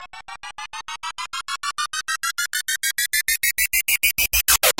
Message Tones
message , sms , tone , notification , text , zedgetones ,